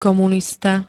komunista [-n-] -tu pl. N -ti m.